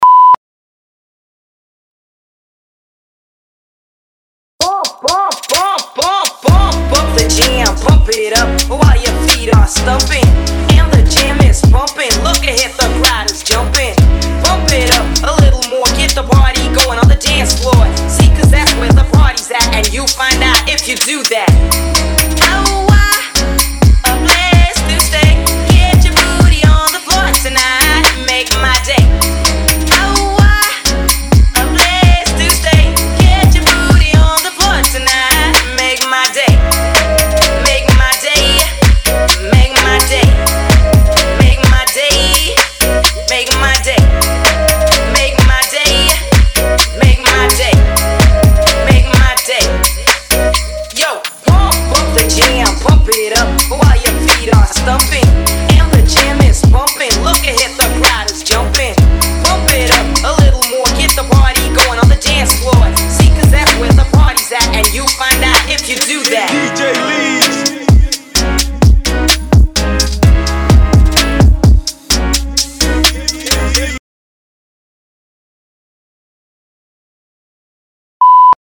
*bleep: Are you on your spot?
*3 seconds silence: to look better on screen to be sure that you are not walking anymore
*few seconds silence: stay in your place till you hear the next bleep
Solo Music: